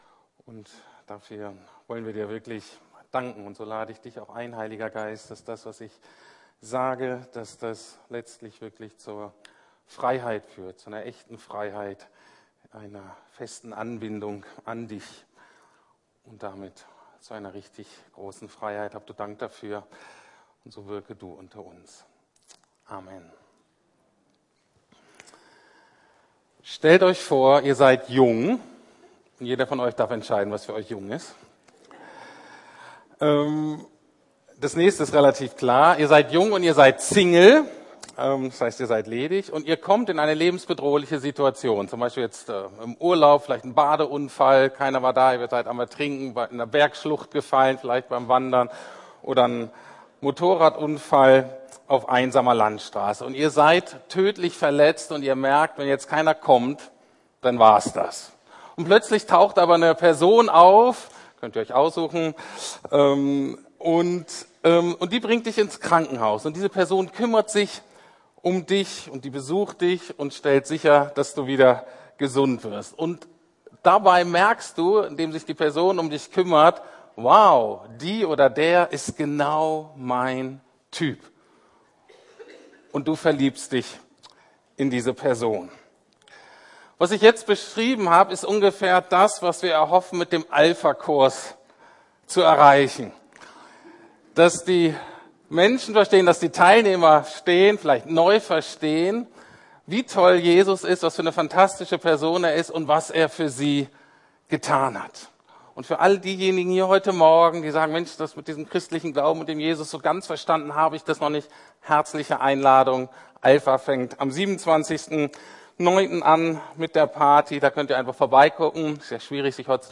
Lieben, was Jesus liebt: Israel und die Juden ~ Predigten der LUKAS GEMEINDE Podcast